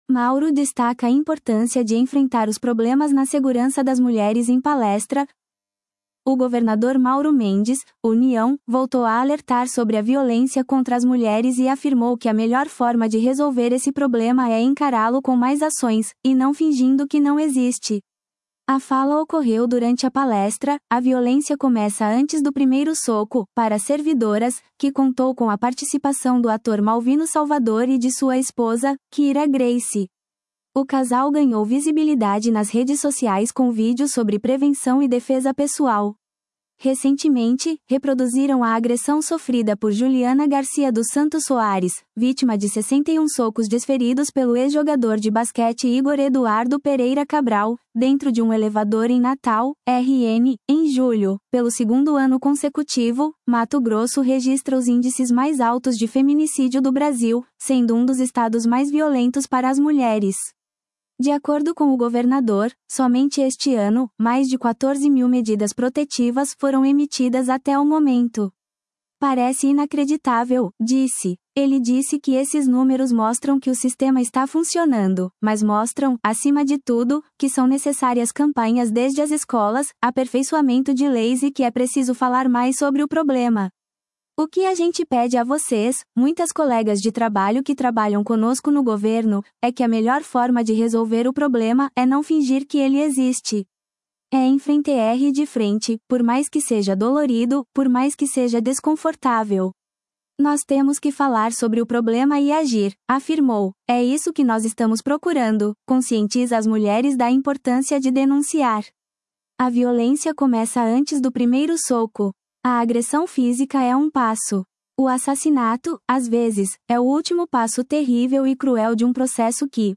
Mauro destaca importância de enfrentar os problemas na segurança das mulheres em palestra